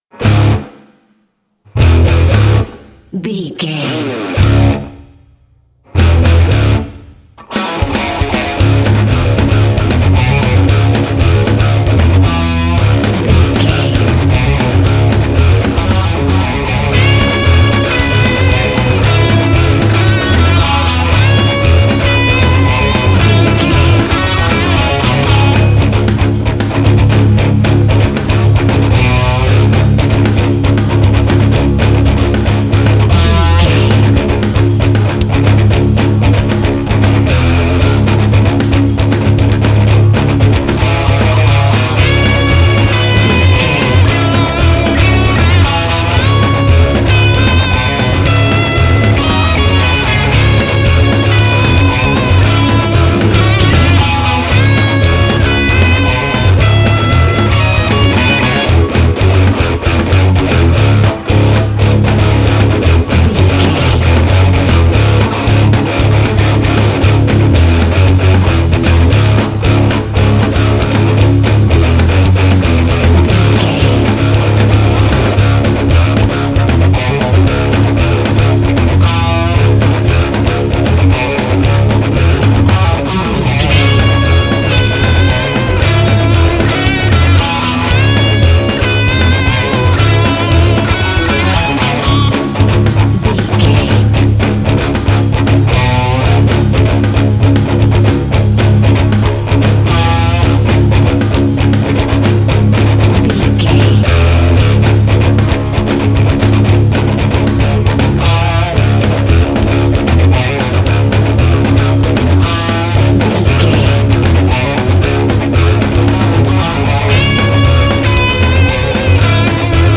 Aeolian/Minor
C♯
Fast
aggressive
intense
driving
dark
heavy
bass guitar
electric guitar
drum machine